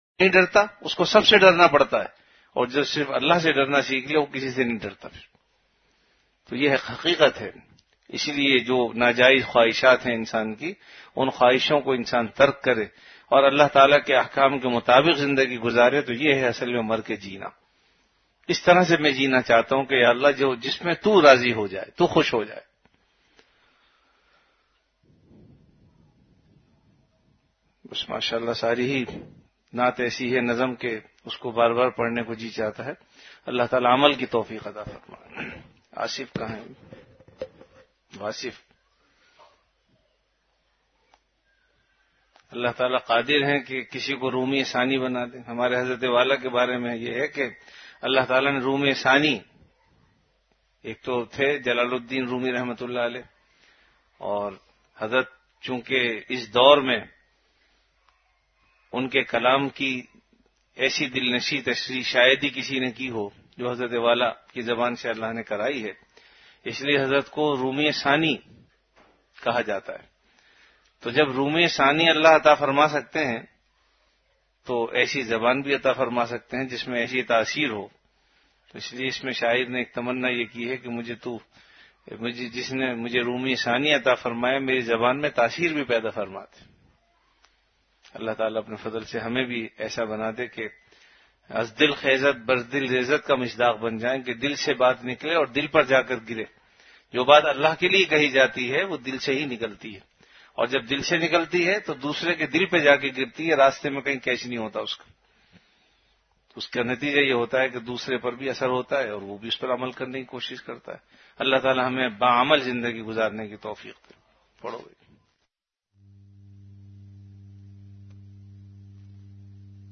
Delivered at Home.
Majlis-e-Zikr · Home Majlis e Zikr